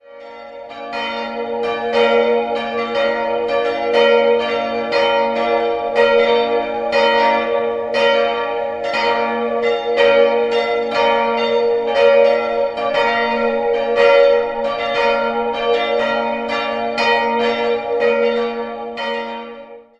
Jahrhunderts. 3-stimmiges B-Dur-Geläute: b'-d''-f'' Die Glocken wurden im Jahr 1950 von der Gießerei Ludwig Will in Bruckberg gegossen.